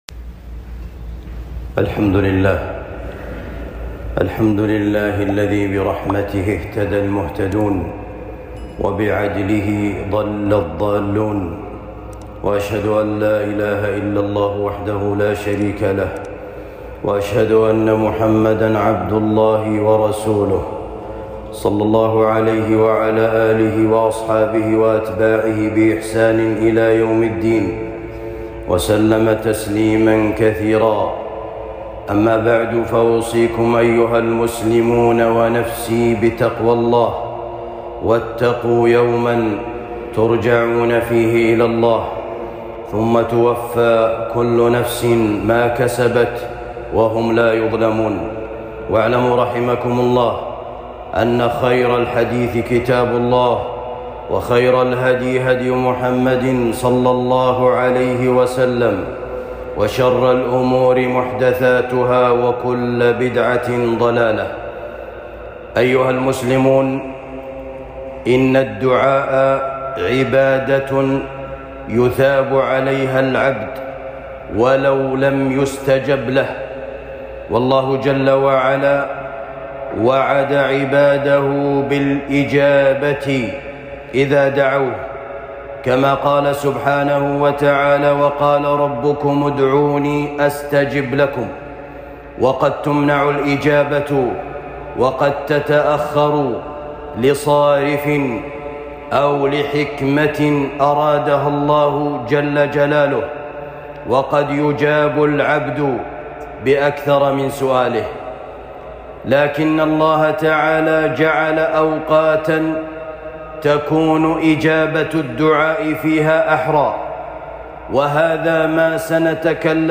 مواطن إجابة الدعاء خطبة جمعة